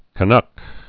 (kə-nŭk)